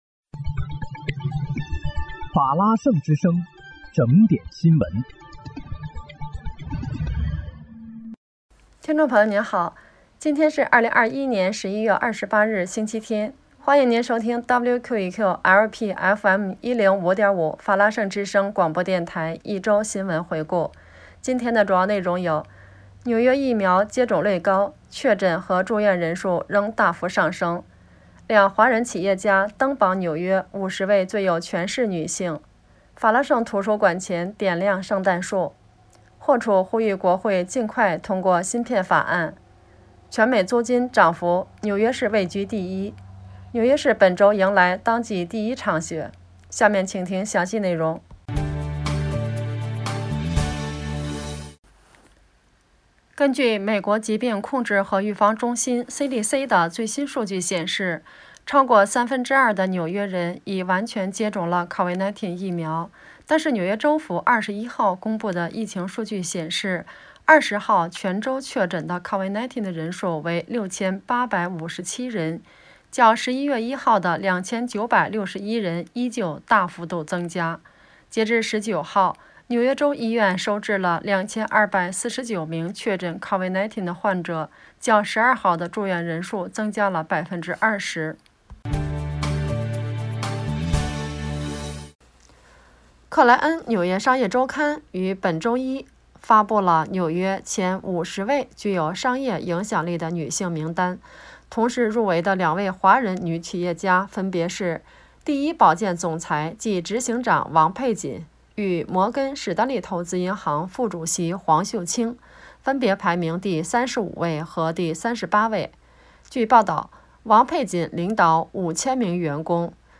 11月28日（星期日）一周新闻回顾